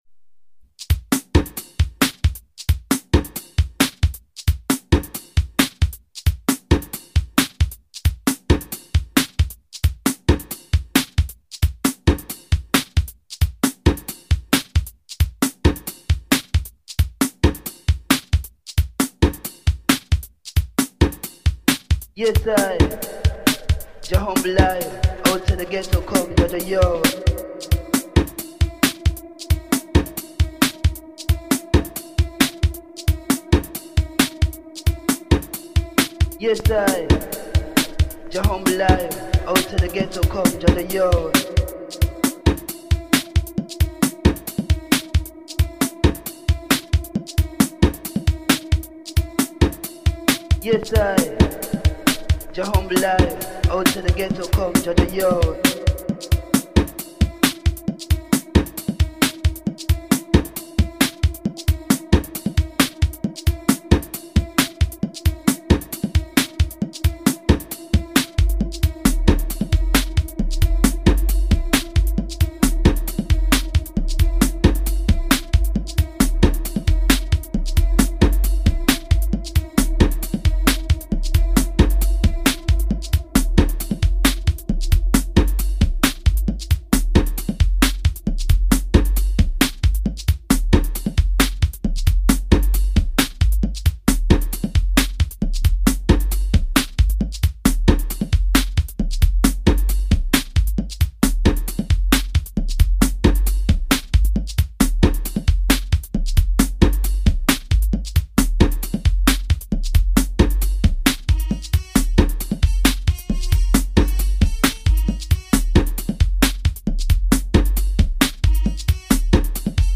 DUB I STEPPERS...